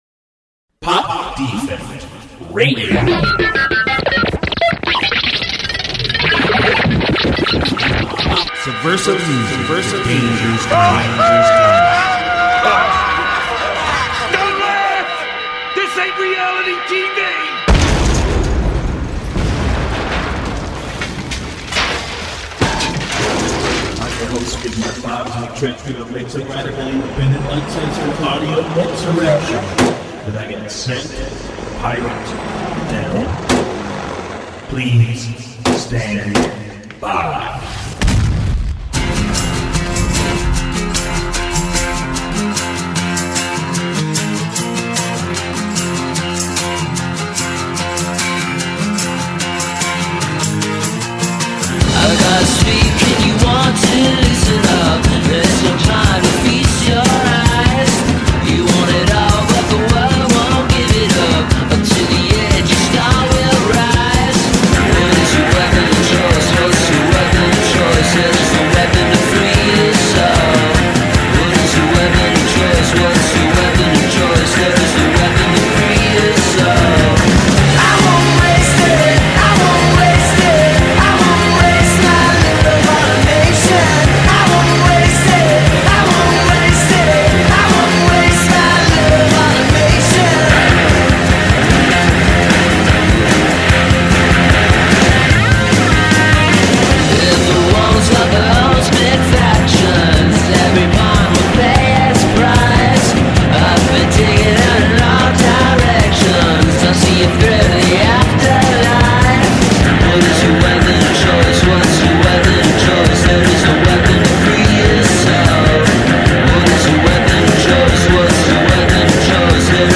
128k stereo
Punk/hardcore
Folk/aRock/blues